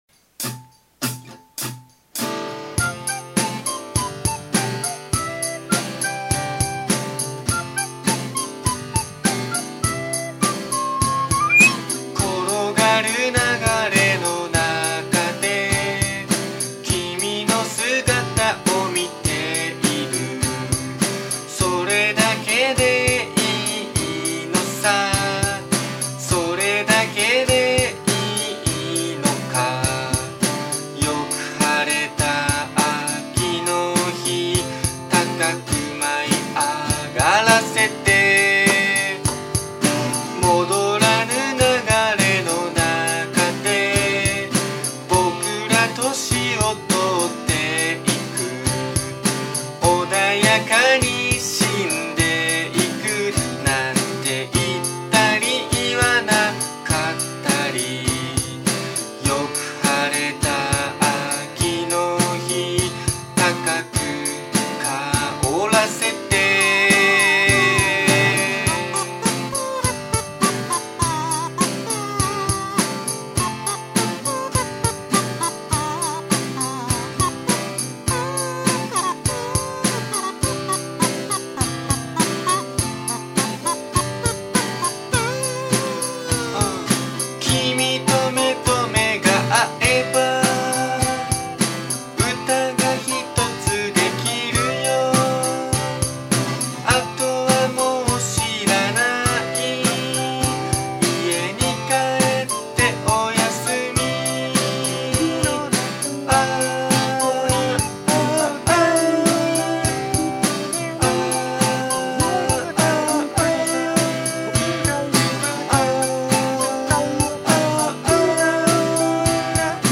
【ジャンル】 ポップス
使用楽器はリコーダー、アコギ、カズー、リズムマシーンです。